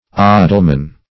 Search Result for " odalman" : The Collaborative International Dictionary of English v.0.48: Odalman \O"dal*man\, Odalwoman \O"dal*wom`an\, n. (Teut.
odalman.mp3